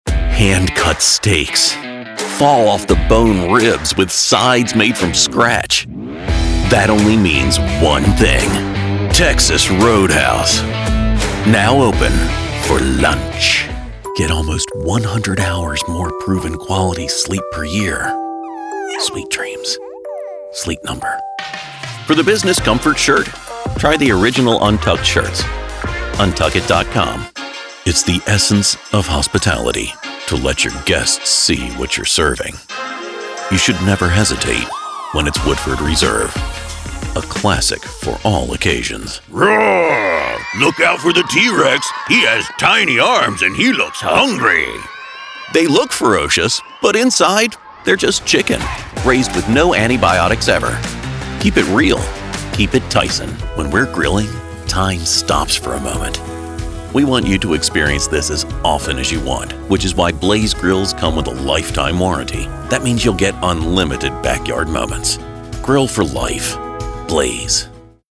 A versatile voice that blends commercial charm with a touch of whimsy!
0712Commercial_Demo.mp3